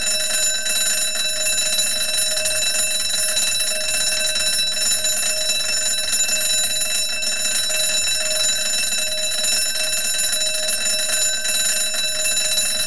ding2
bell ding ring small sound effect free sound royalty free Sound Effects